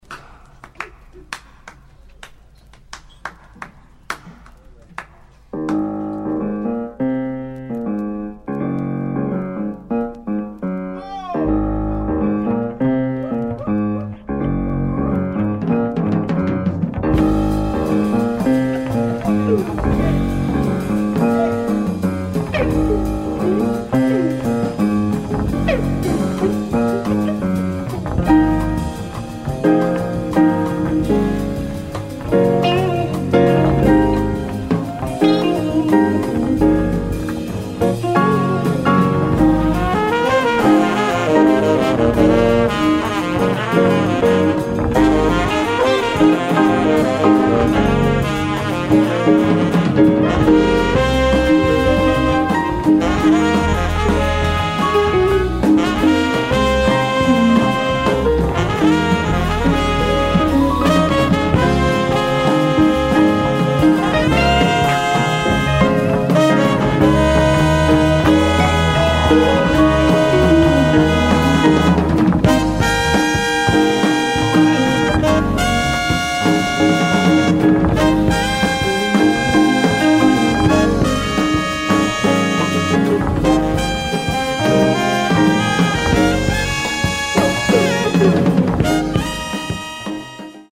Recorded live in Zurich.